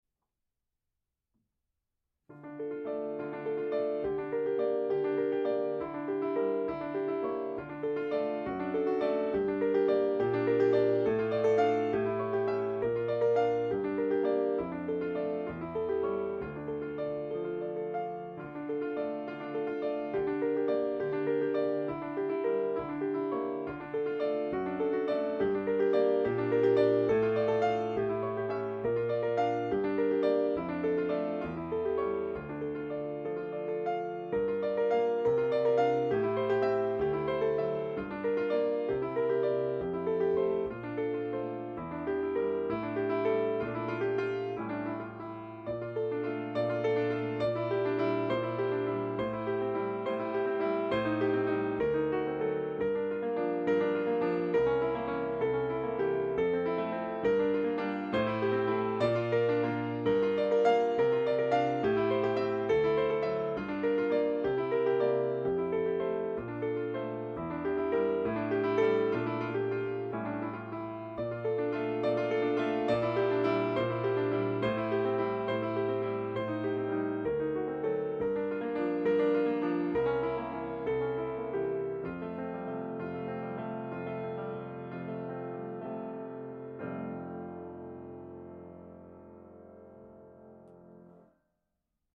piano - romantique - melancolique - nostalgique - melodique